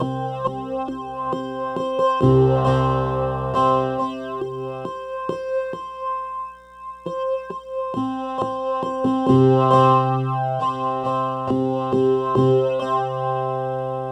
StuckPlucker2_136_C.wav